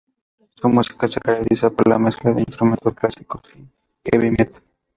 /meˈtal/